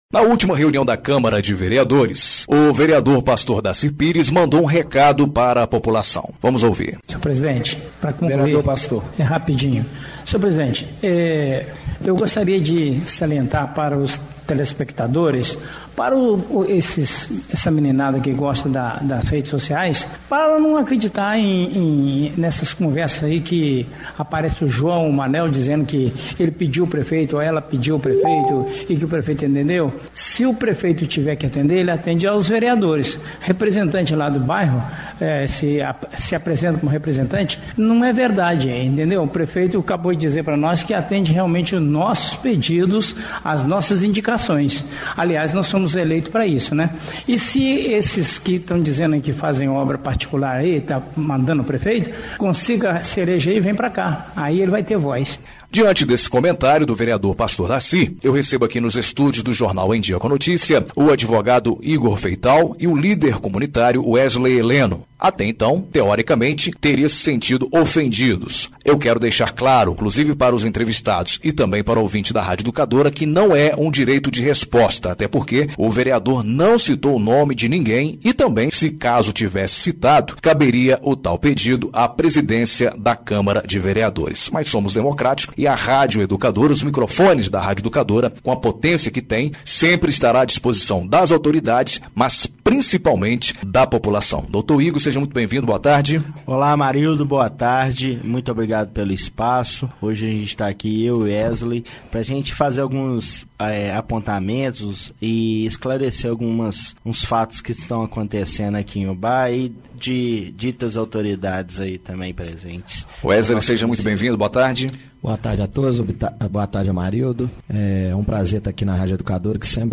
ENTREVISTA EXIBIDA NA RÁDIO EDUCADORA AM/FM UBÁ – MG